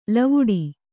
♪ lavuḍi